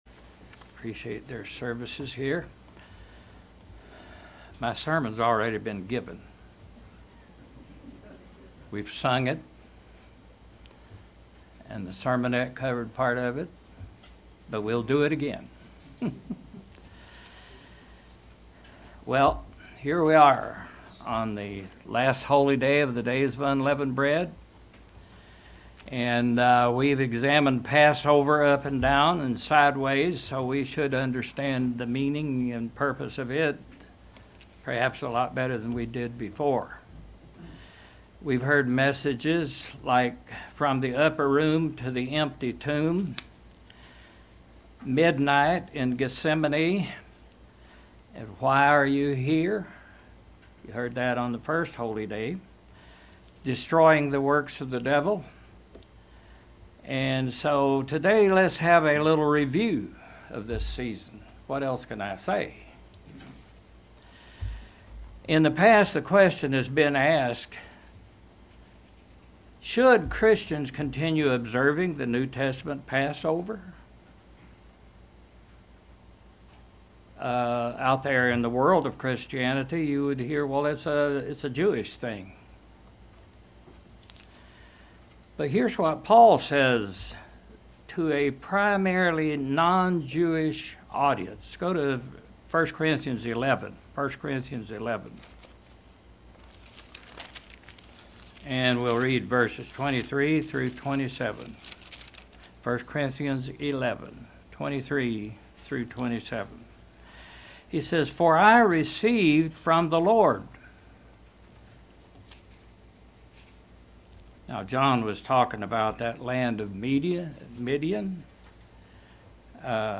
A message reviewing the historic as well as the ongoing significance and spiritual implications of the Days of Unleavened Bread.